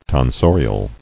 [ton·so·ri·al]